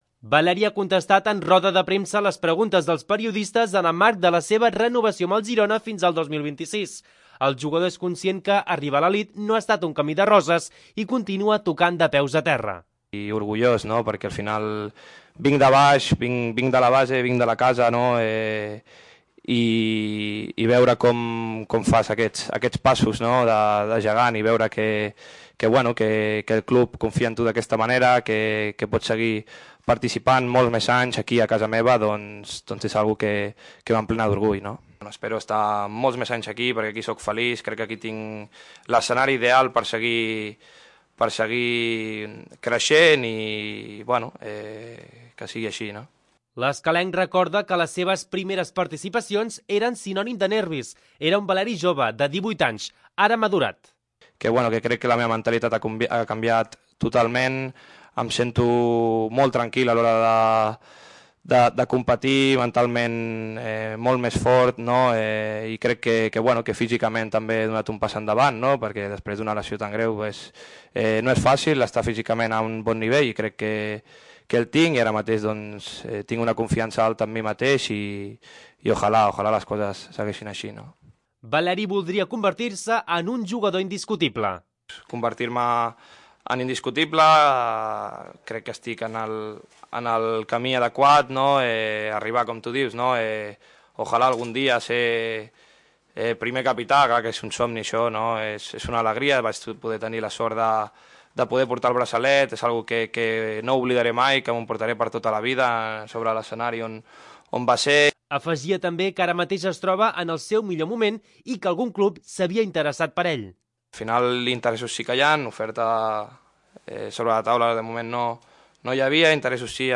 Valery explica la seva renovació en roda de premsa
Valery ha contestat en roda de premsa les preguntes dels periodistes en el marc de la seva renovació amb el Girona fins al 2026.